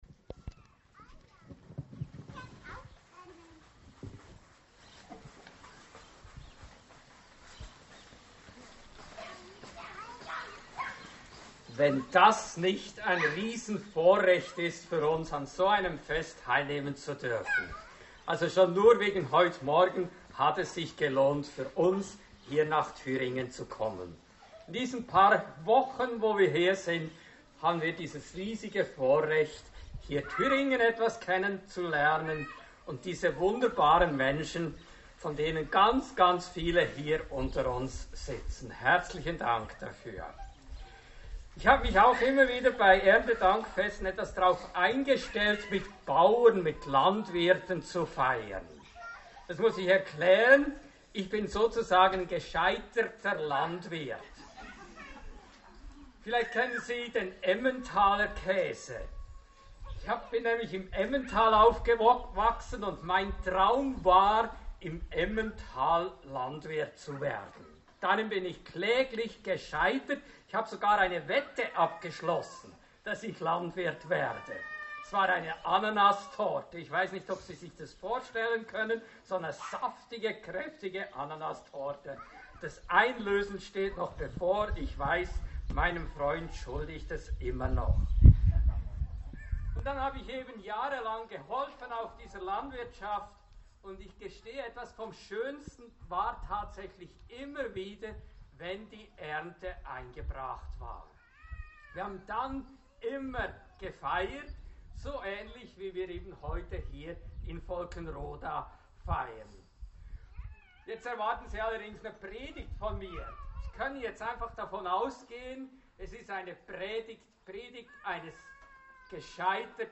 Predigt zum Erntedankfest.